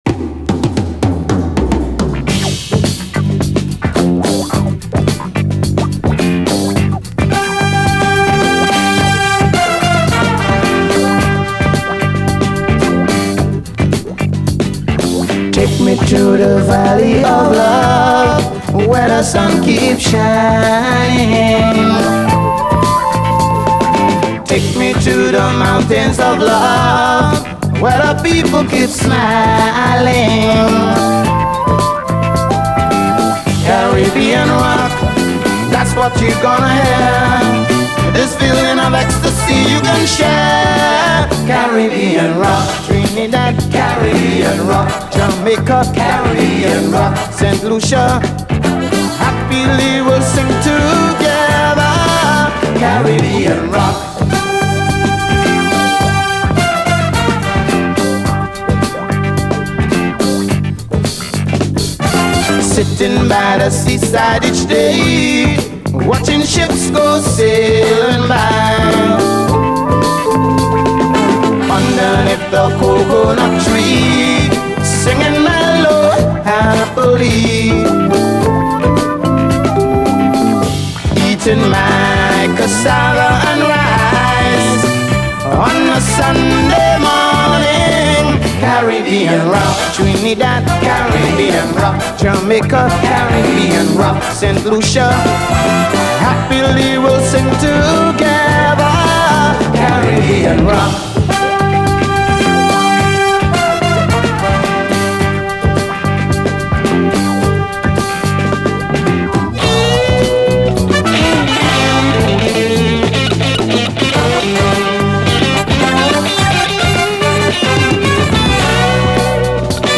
SOUL, 70's～ SOUL, DISCO, CARIBBEAN, 7INCH
ドイツ産の最高カリビアン・ファンキー・ロック・チューンのピンポイントな７インチ！
ご陽気な曲のようでソウルフル＆ファンキーな厚みのあるトコがイカしてます！